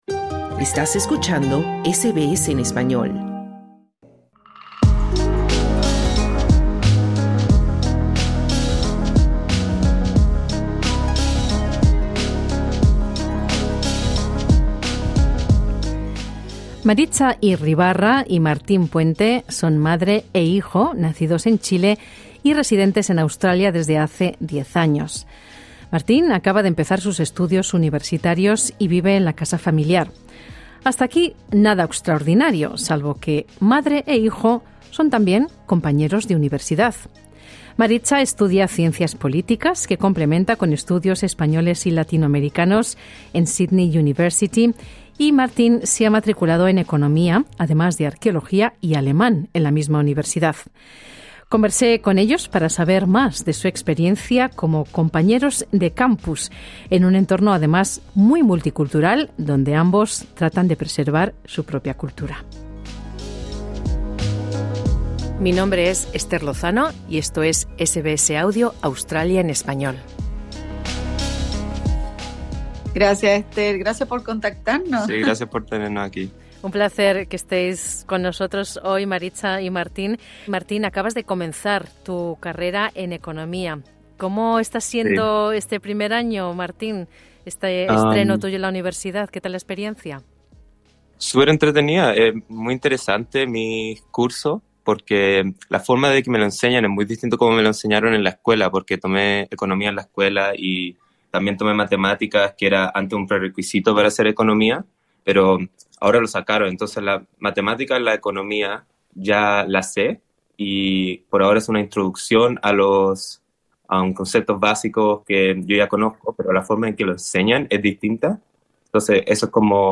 En conversación con SBS Spanish, comparten sus desafíos y motivaciones.